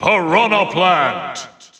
The announcer saying Piranha Plant's name in English releases of Super Smash Bros. Ultimate.
Piranha_Plant_English_Announcer_SSBU.wav